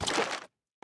sounds / mob / strider / step3.ogg